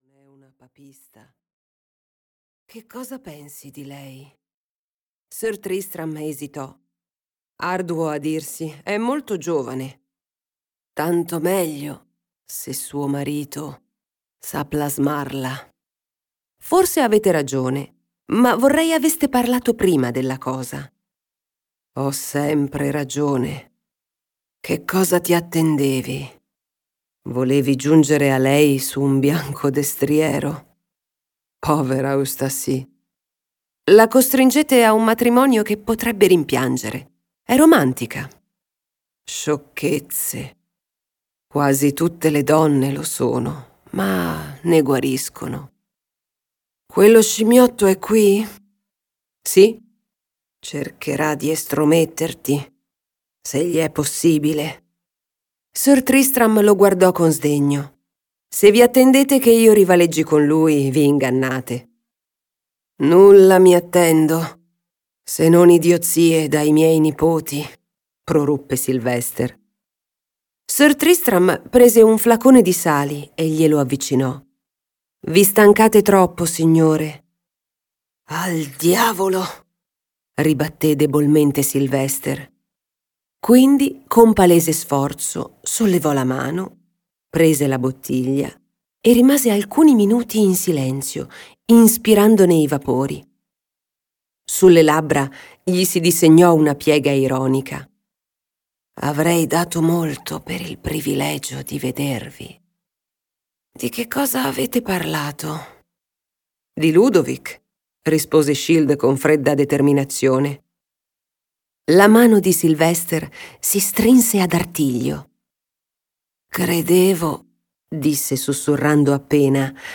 "L'anello" di Georgette Heyer - Audiolibro digitale - AUDIOLIBRI LIQUIDI - Il Libraio